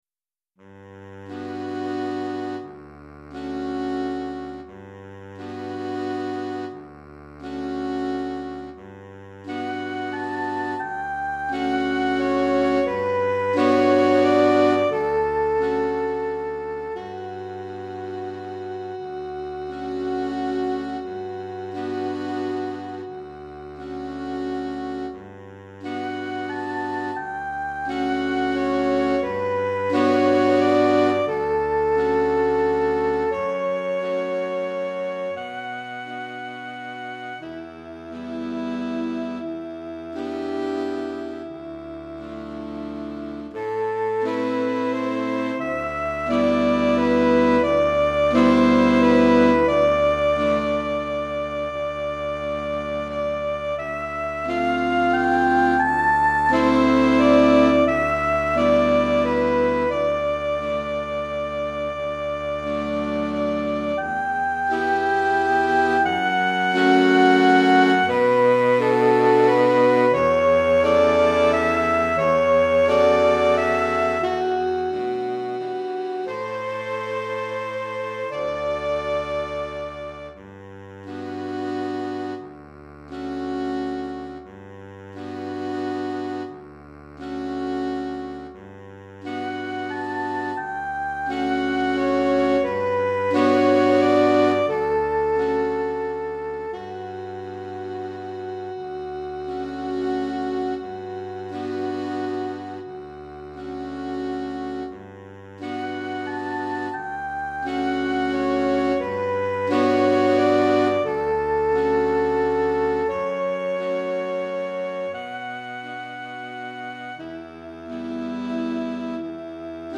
5 Saxophones